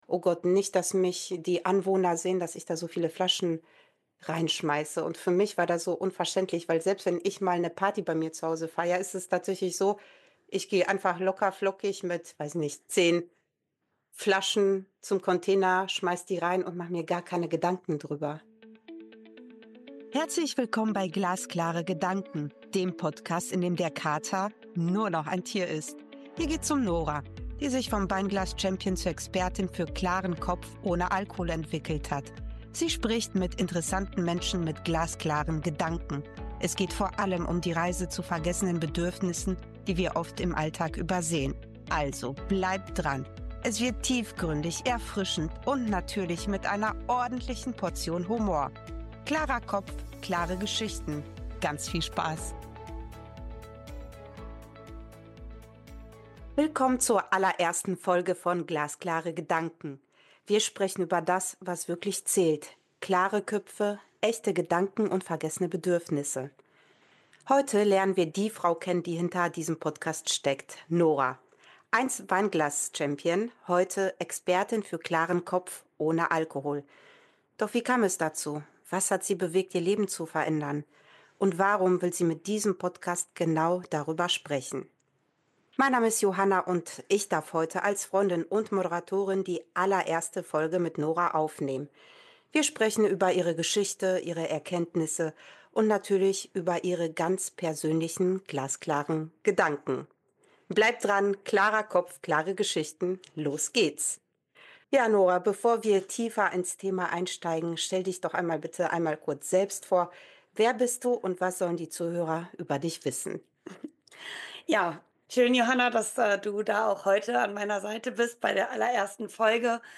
Meine enge Freundin und Wegbegleiterin führt dieses Gespräch mit mir – ehrlich, offen und ohne Tabus. Wir reden über die Herausforderungen, die Ängste, aber auch über die schönen Momente, die mich auf diesem Weg begleitet haben.